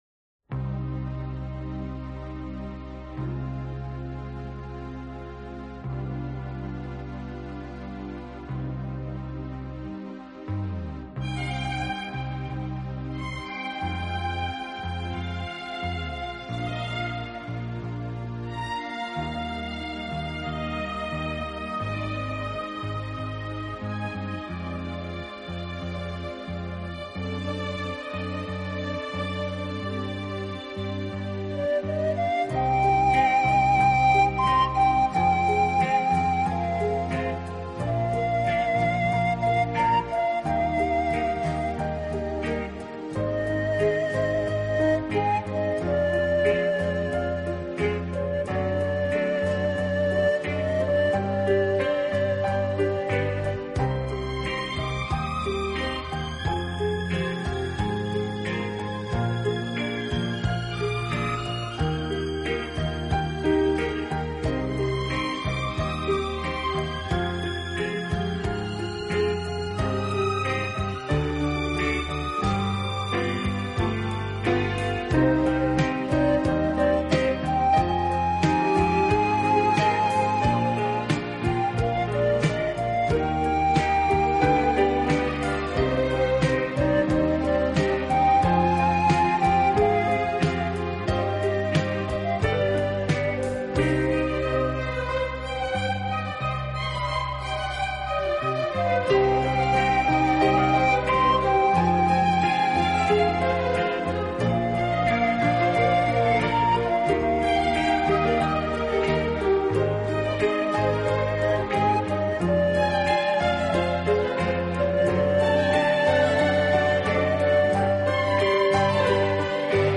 的清晰度，准确的结像力，极宽的动态范围，更逼真的现场效果，更细腻纯正的音
排萧的最大魅力在于它明晰的穿透力，背景音乐中，排萧的乐声仿佛如从远处飘至
，但仍清晰可闻，舒缓而悠扬。
而又觉得安详和甜美，如同在阳光下沐浴，在森林中畅想，是令人迷恋的天籁之音……